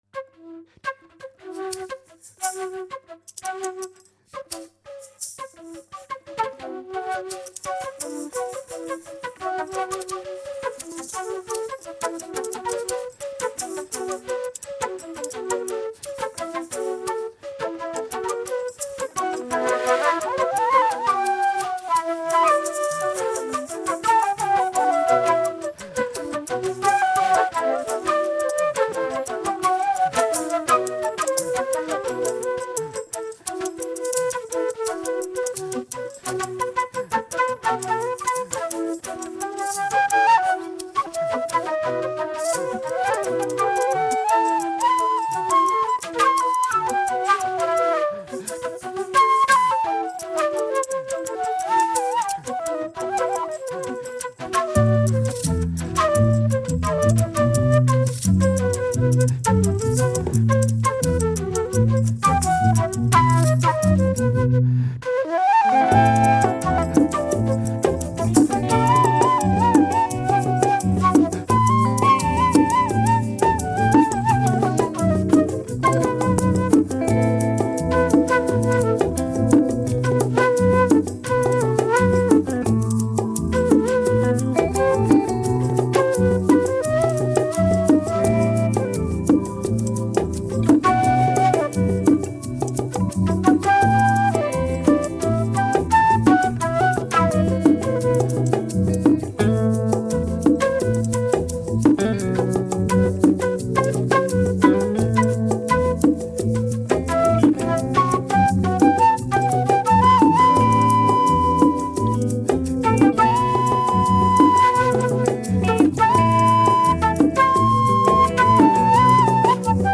Жанр: Folk.